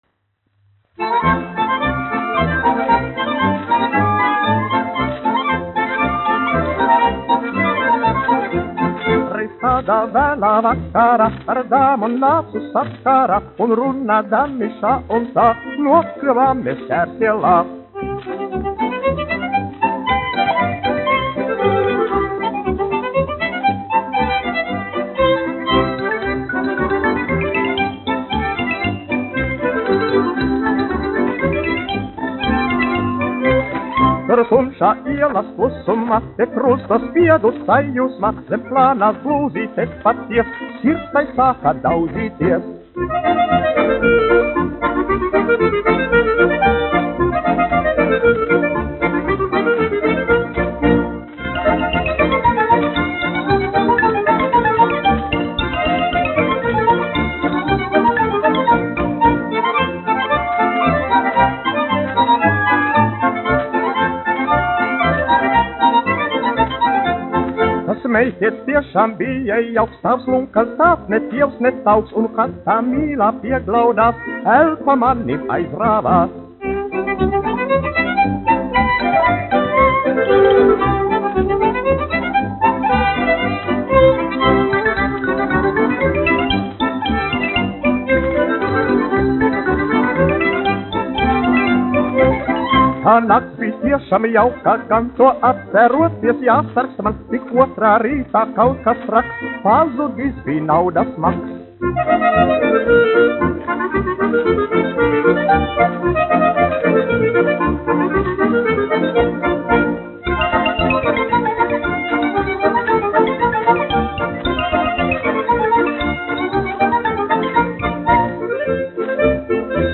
dziedātājs
1 skpl. : analogs, 78 apgr/min, mono ; 25 cm
Polkas
Latvijas vēsturiskie šellaka skaņuplašu ieraksti (Kolekcija)